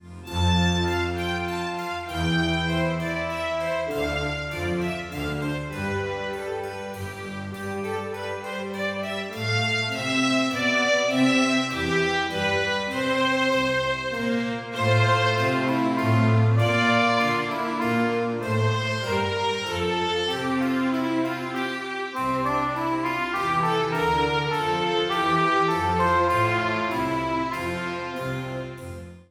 minuetoB.mp3